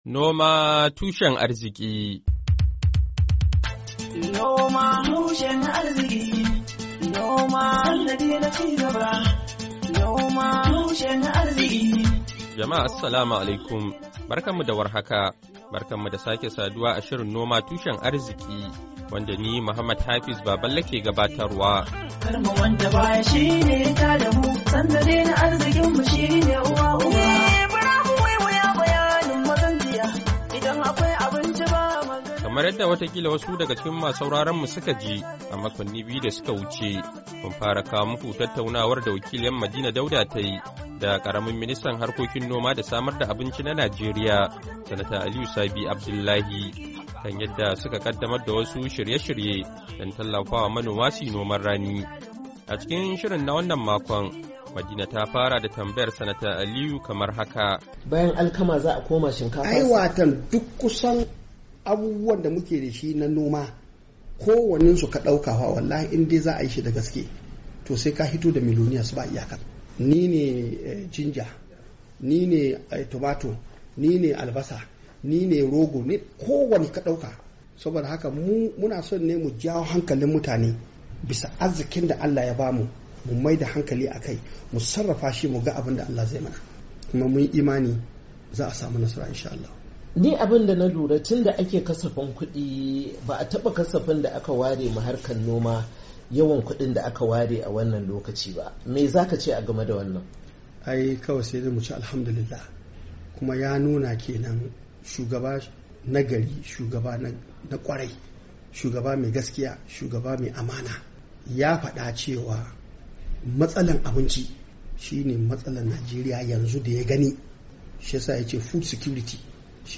NOMA TUSHEN ARZIKI: Hira Da Ministan Harkokin Noma Da Samar Da Abinci Na Najeriya Kan Noman Rani Na Bana, Kashi Na Uku - Fabrairu 06, 2024